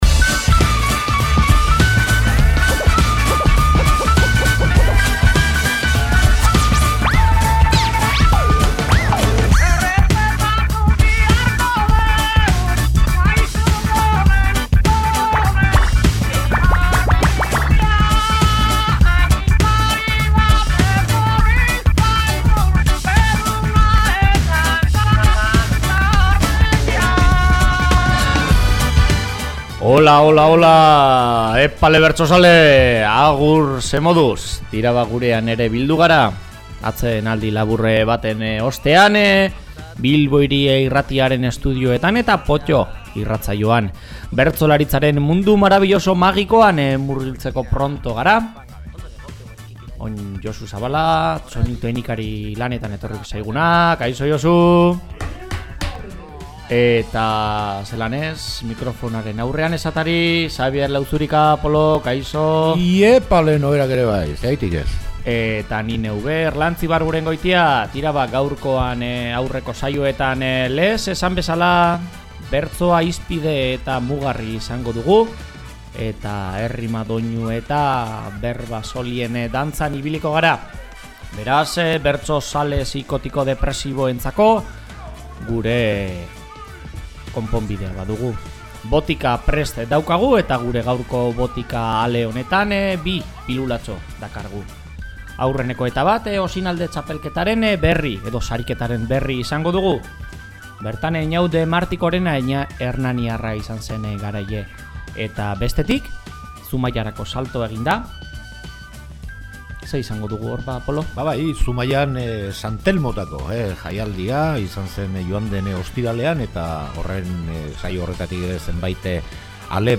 Gaurko saioan, Zumaiako santelmotako jaialdia eta Gabiriako Osinalde Sariketako zenbait ale ditugu entzungai. Bertsoaren munduko zenbait albiste eta agenda ere Potto-n dira.